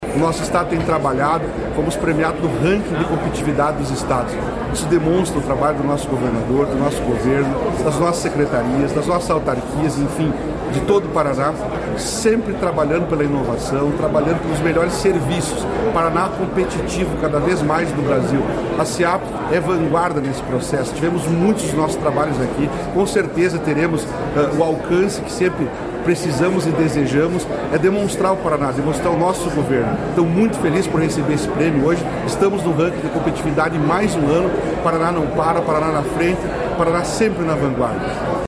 Sonora do secretário da Administração e da Previdência, Elisandro Frigo, sobre o Paraná ser o terceiro estado mais competitivo do Brasil